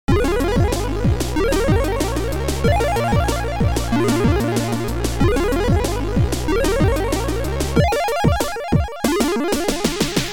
Future Composer Module